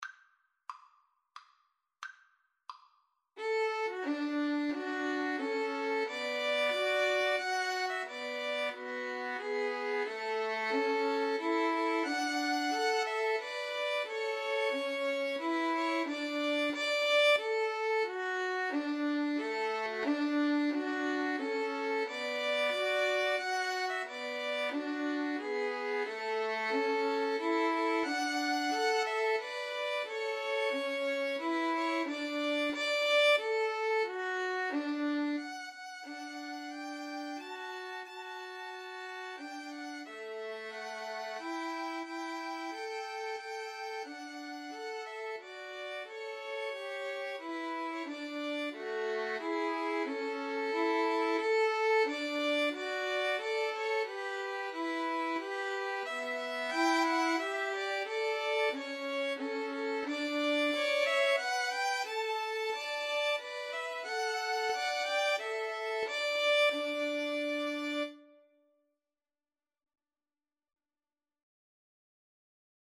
D major (Sounding Pitch) (View more D major Music for Violin Trio )
Maestoso = c.90
Traditional (View more Traditional Violin Trio Music)
star_spangled_banner_3VLN_kar2.mp3